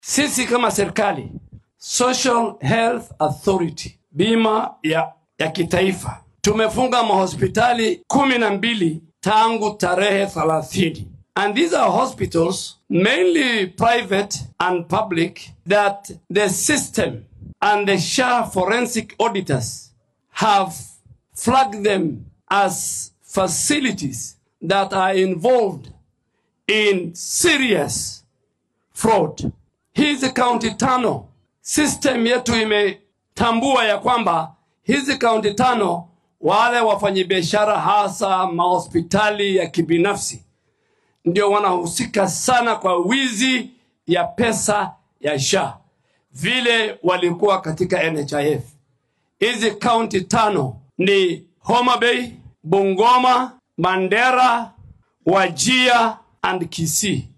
Wasiirka Caafimaadka Aadan Barre Ducaale ayaa shaaciyey shan dowlad deegaan oo caan ku ah musuqmaasuqa ku saabsan Maamulka Caymiska Caafimaadka Bulshada ee (SHA), iyadoo dowladda ay xoojineyso olole qaran oo lagula dagaallamayo sheegashooyinka caafimaad ee been abuurka ah. Ducaale, oo Sabtidii ka hadlay ismaamulka Gaarisa ayaa tilmaamay dowlad deegaannada Homa Bay, Bungoma, Mandheera, Wajeer iyo Kisii inay yihiin kuwa ugu sareeya ee leh sheegashooyin shaki leh, badankoodna ka yimid xaruumo caafimaad oo gaar loo leeyahay.